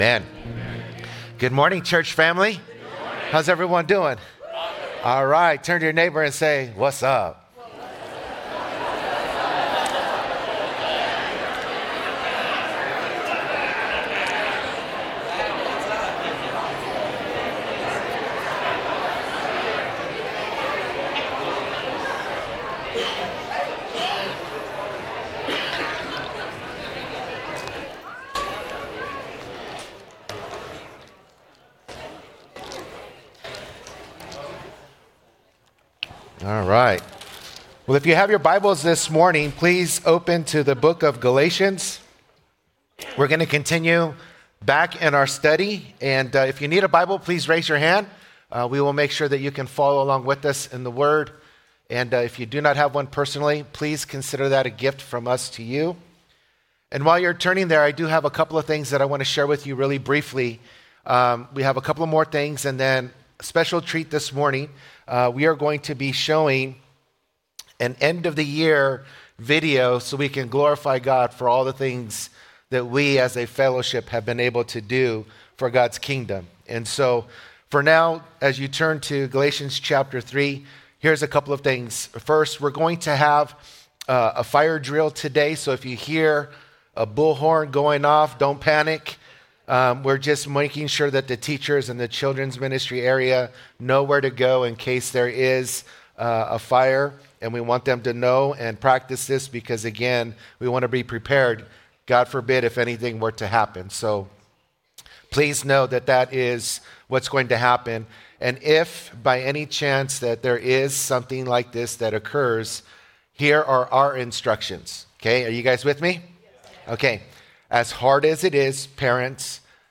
Calvary Chapel Saint George - Sermon Archive
Sunday Mornings